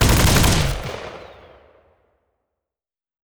combat / weapons / mgun / fire1.wav
fire1.wav